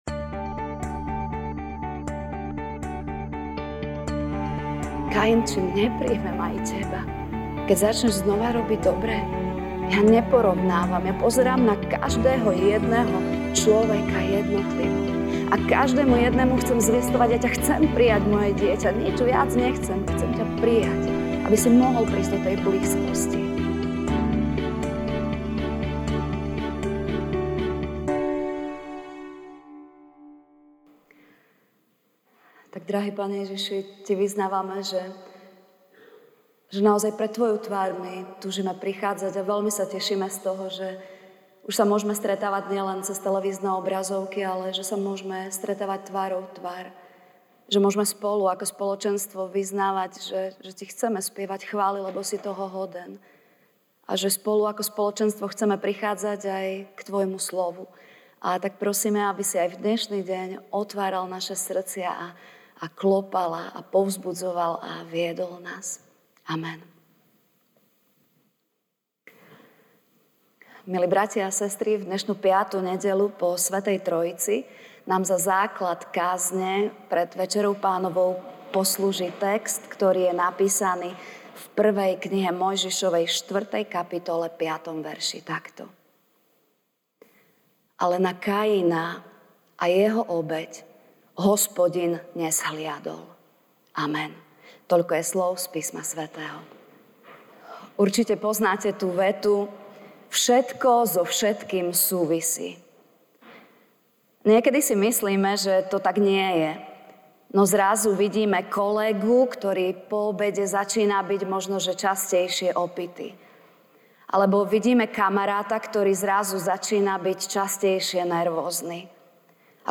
júl 04, 2021 Múr v nasledovaní MP3 SUBSCRIBE on iTunes(Podcast) Notes Sermons in this Series Ranná kázeň: 1M (4, 5a) „ ...ale na Kaina a na jeho obeť nezhliadol.“